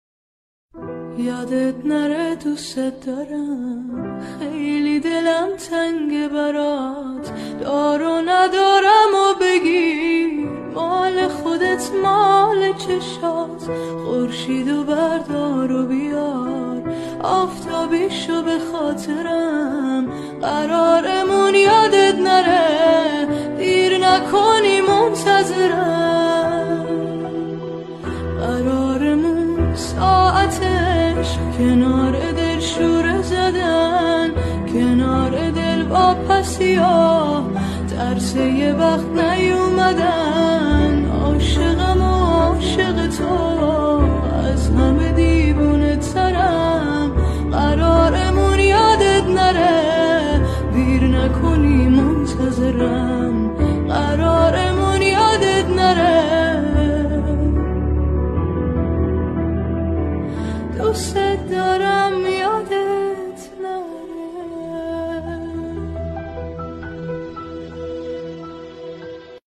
با صدای زن (تمام خوانندگان)